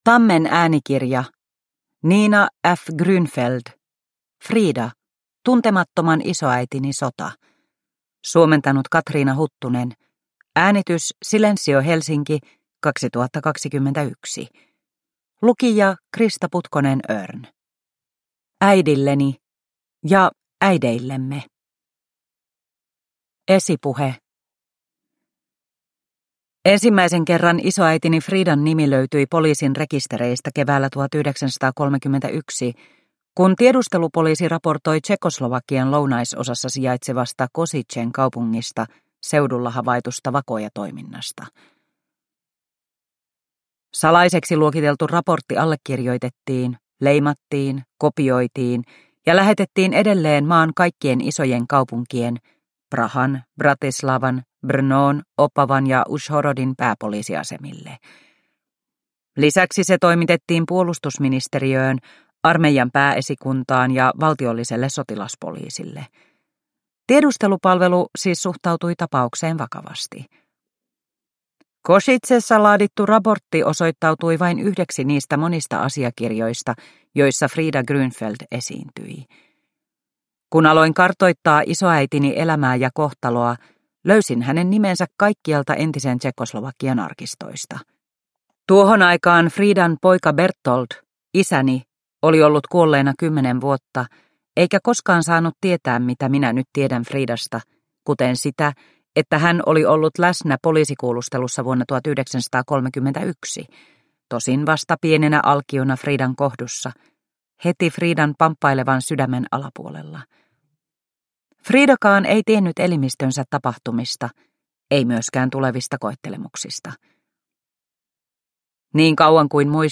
Frida - Tuntemattoman isoäitini sota – Ljudbok – Laddas ner